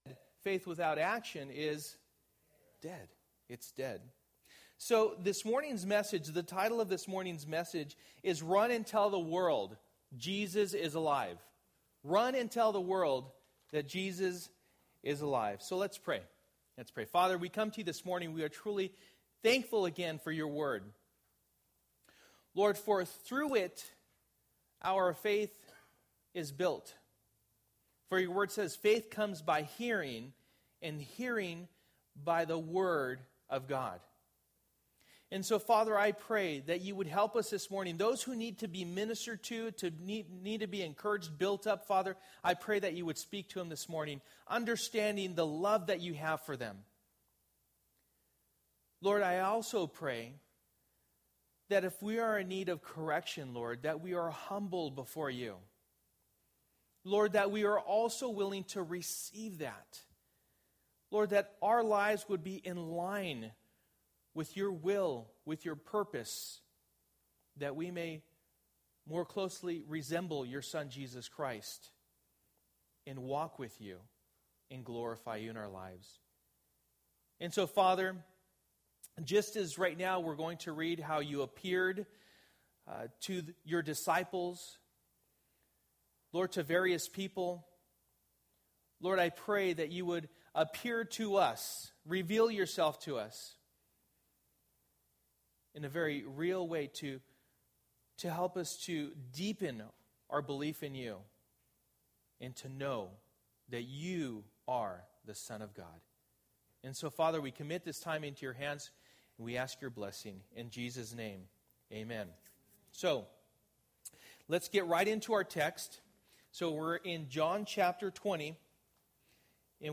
Believe Passage: John 20:1-31 Service: Sunday Morning %todo_render% « The Cross Draws All Men to God Saved!